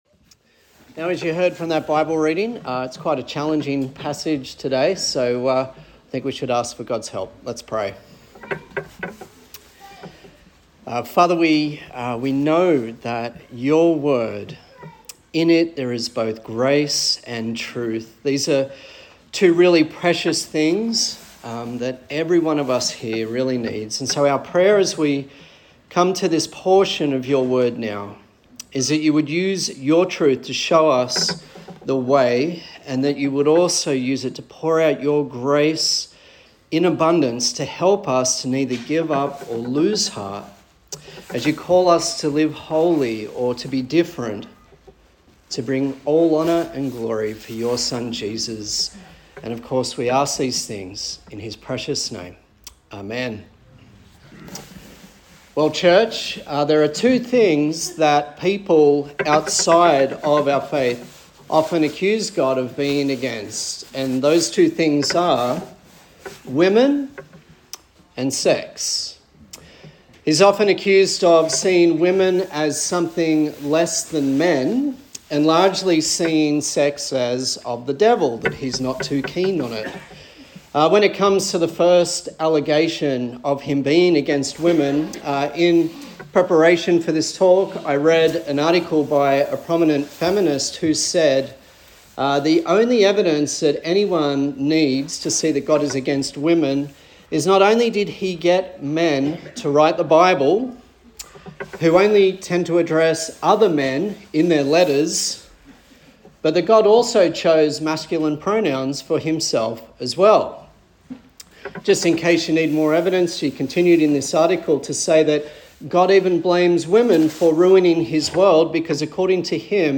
Leviticus Passage: Leviticus 12 and 15 Service Type: Sunday Service